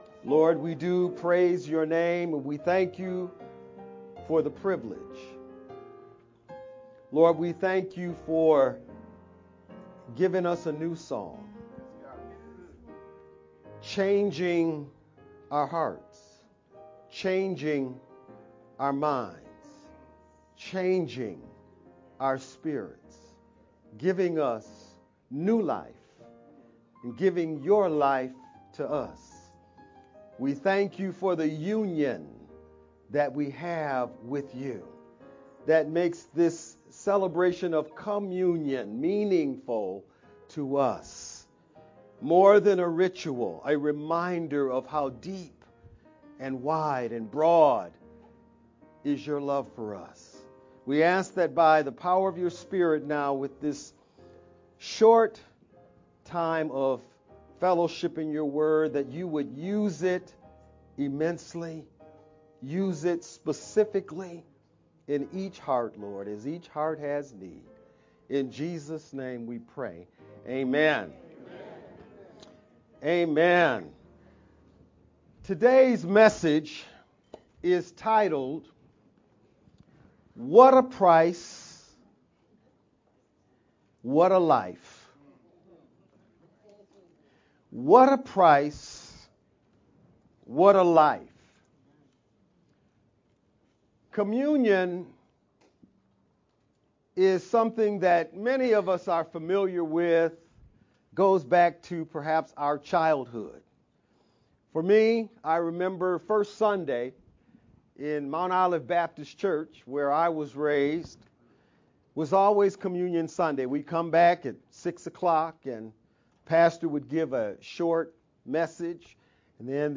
July-28th-VBCC-sermon-only_Converted-CD.mp3